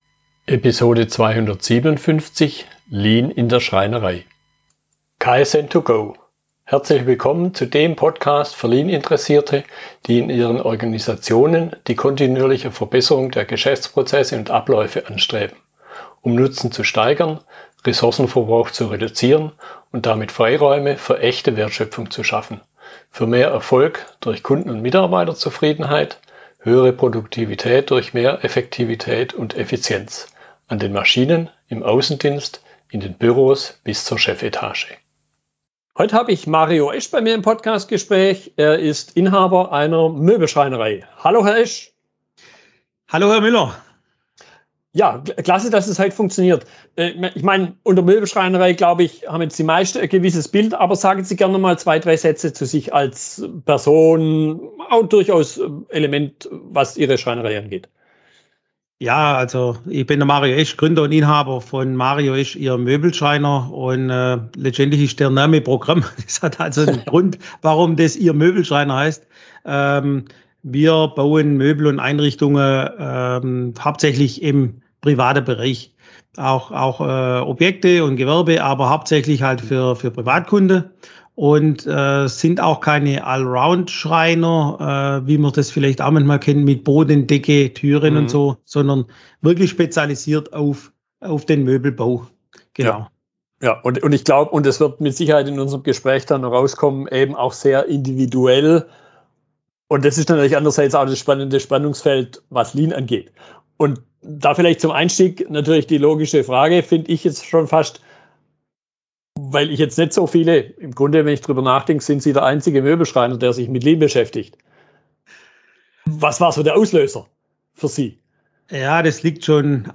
Fragestellungen aus der Unterhaltung